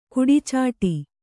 ♪ kuḍicāṭi